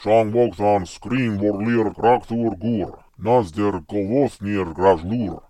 It incorporates a range of vocalizations, alternating between normal voice production and deep, guttural growls, reminiscent of the Fomorians' intimidating presence.